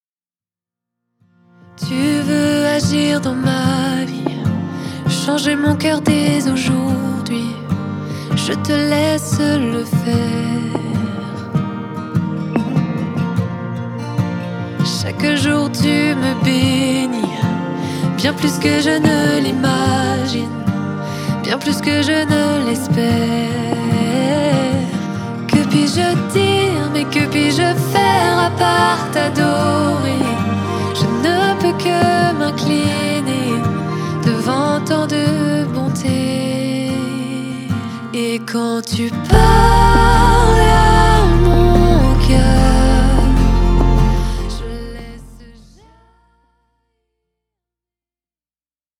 pop louange